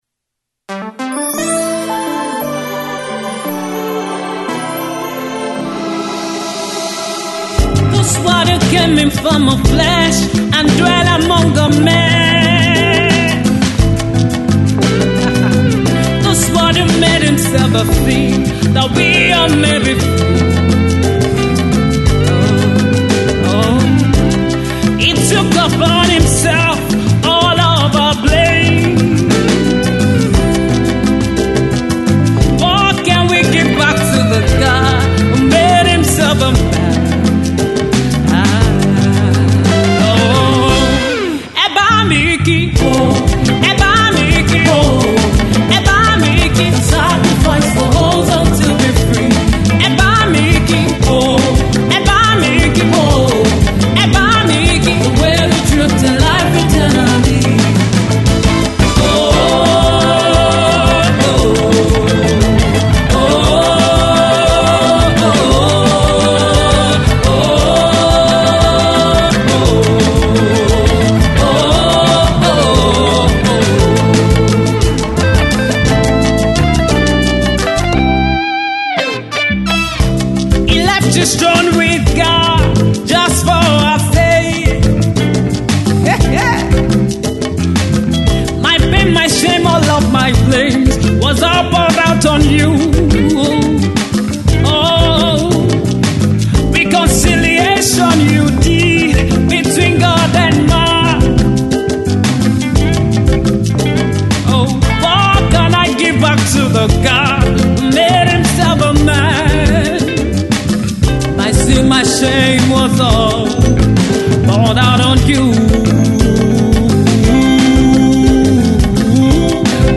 spirit filled singer
gospel music